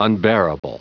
Prononciation du mot unbearable en anglais (fichier audio)
Prononciation du mot : unbearable